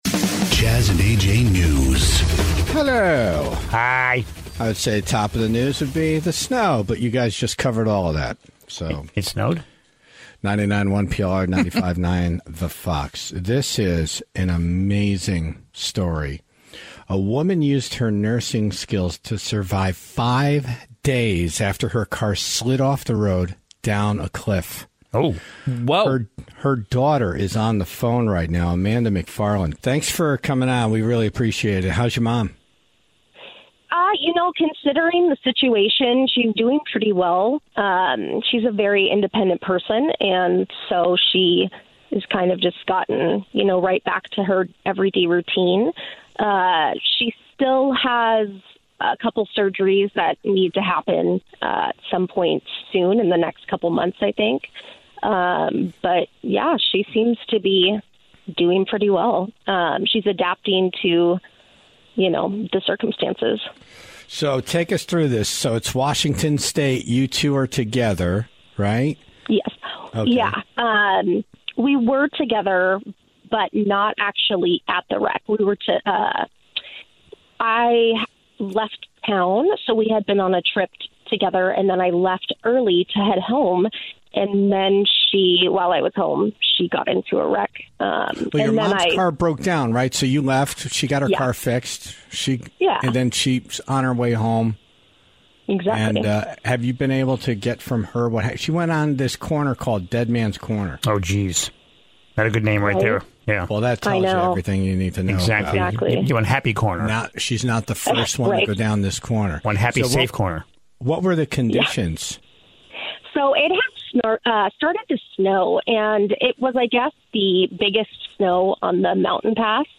on the phone this morning